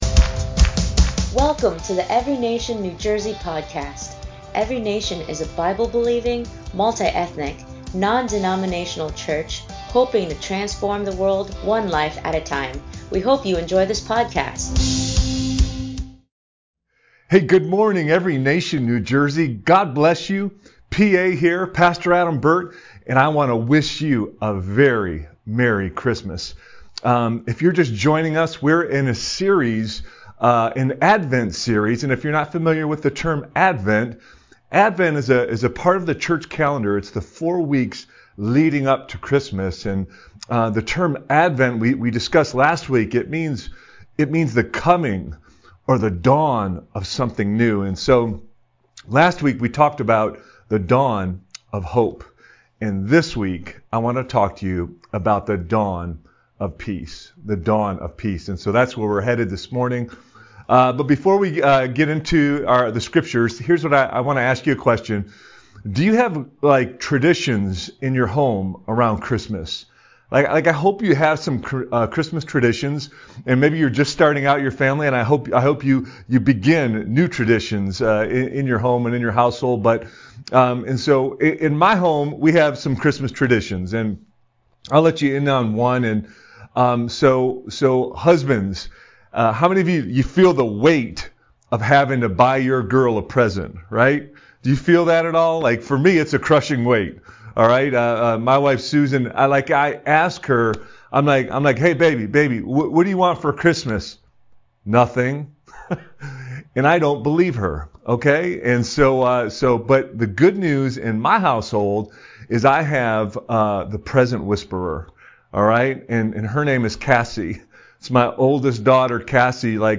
ENCNJ Sermon 2/6/22